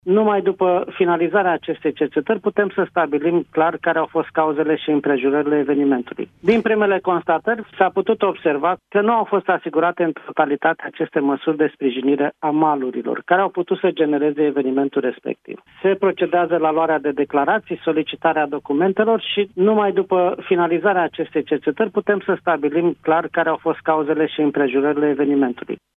Din primele informații, șantierul nu respectA normele privind siguranța in muncă, spune la Europa FM, șeful ITM Bucuresti, Constantin Bujor: